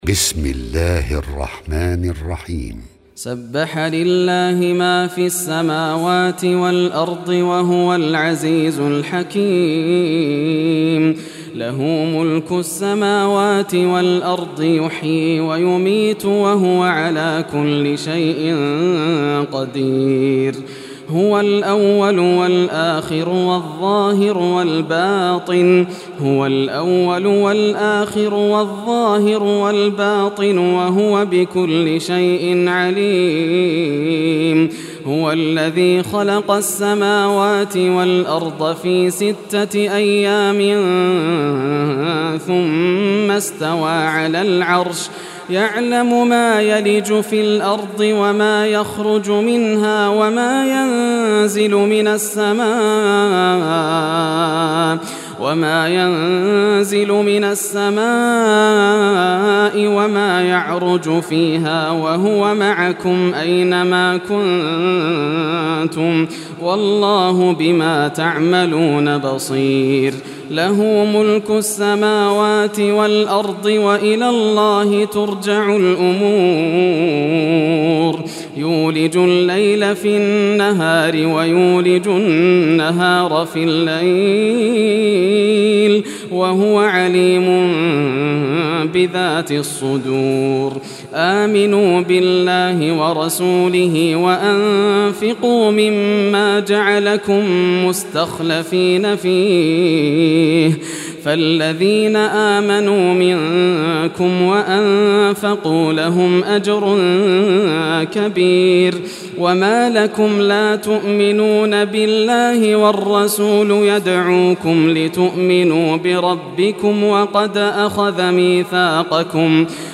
Surah Al-Hadid Recitation by Yasser al Dosari
Surah Al-Hadid, listen or play online mp3 tilawat / recitation in Arabic in the beautiful voice of Sheik Yasser al Dosari.